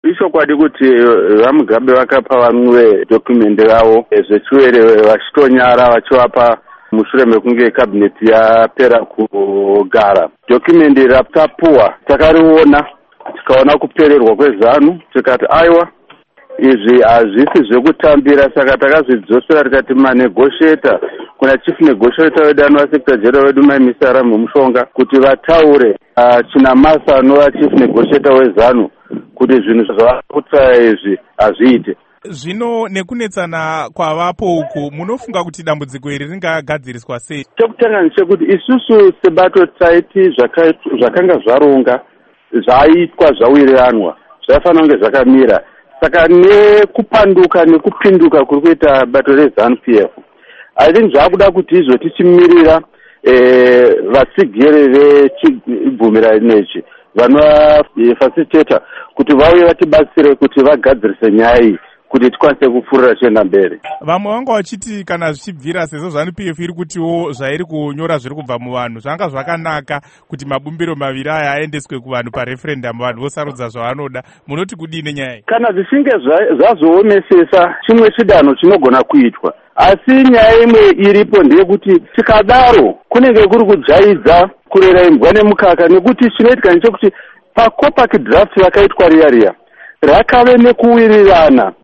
Hurukuro naVaEdwin Mushoriwa